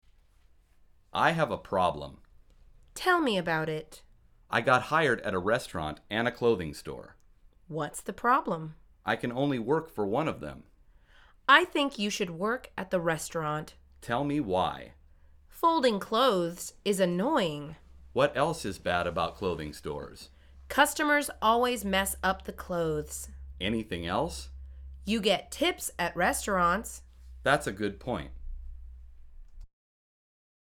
در واقع، این مکالمه مربوط به درس شماره یکم از فصل مشاغل از این مجموعه می باشد.